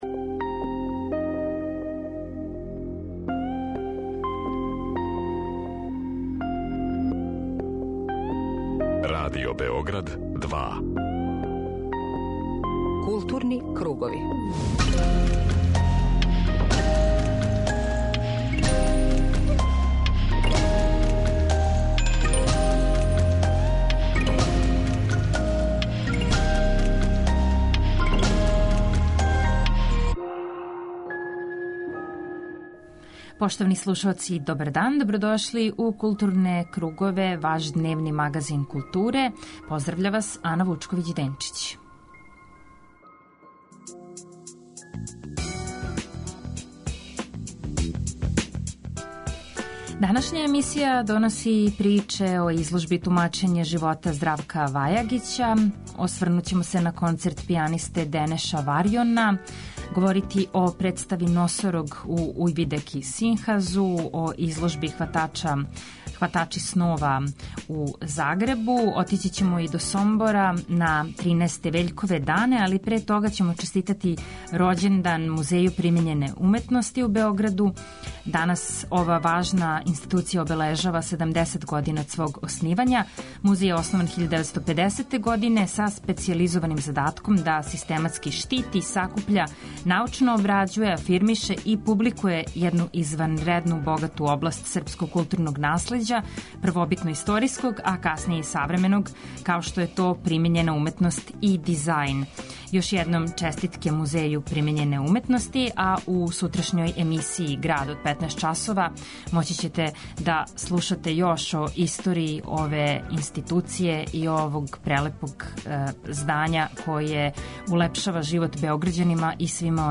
Централна културно-уметничка емисија Радио Београда 2.
У данашњој емисији биће речи и о концерту пијанисте Денеша Варјона на Коларцу, у оквиру БЕМУС-а. Звуком ћемо ићи у Сомбор, где се под покровитељством Града Сомбора и Покрајинског секретаријата за културу одржавају Вељкови дани , посвећени Вељку Петровићу.